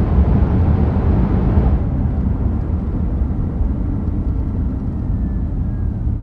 highway / oldcar / stop.ogg